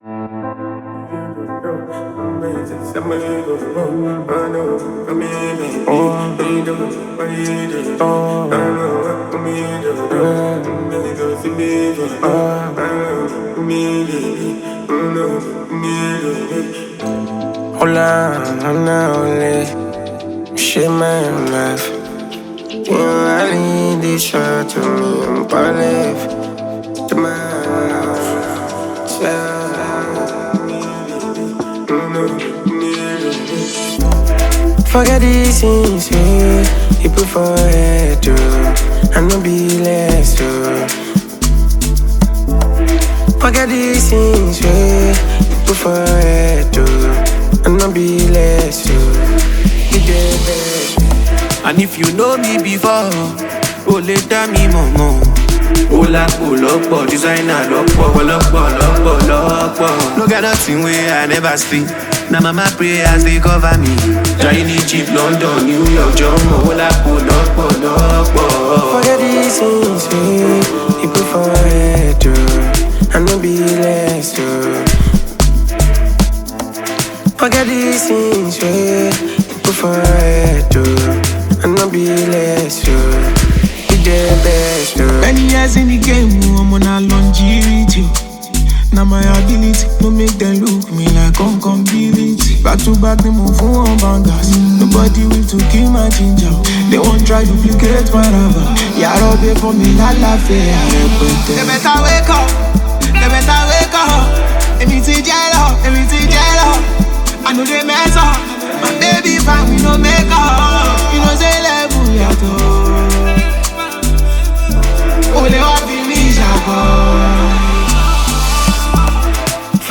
Nigerian singer-songwriter and talented artist